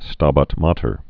(stäbät mätər, stăbăt mātər)